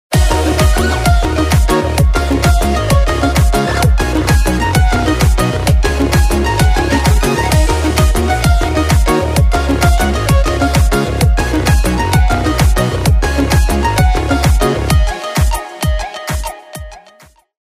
رینگتون پرانرژی و بیکلام